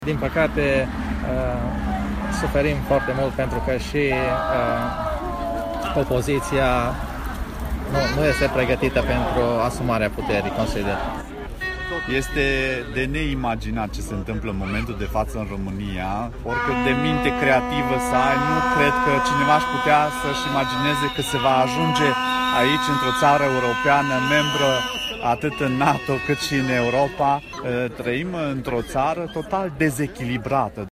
Iată ce spun protestatarii din Baia Mare:
24iun-20-CORESP-MM-VOX-baia-mare.mp3